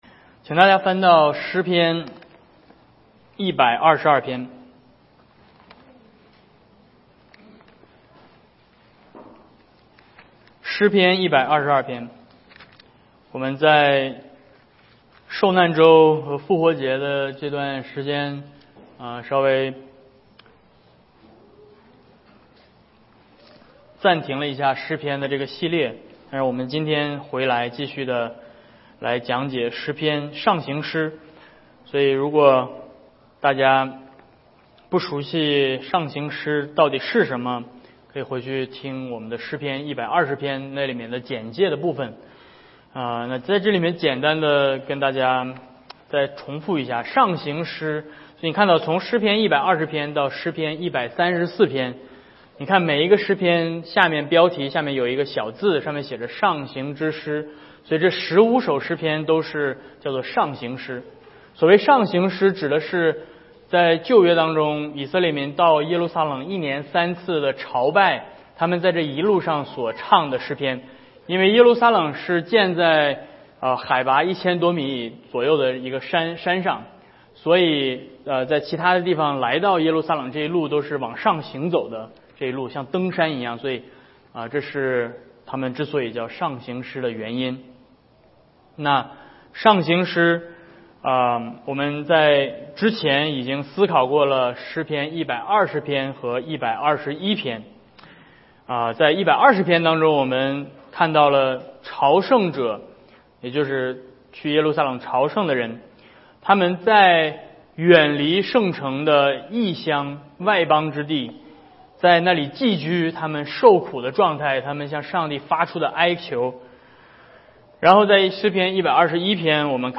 Psalms122 Service Type: 主日讲道 Download Files Notes « 2022复活主日